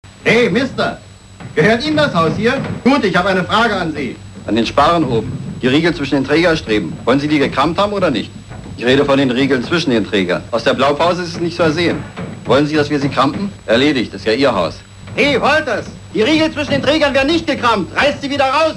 Lex Barker: Vormann der Zimmerleute,  Synchronschauspieler: Reinhard Kolldehoff
Hörprobe des deutschen Synchronschauspielers (294 Kb)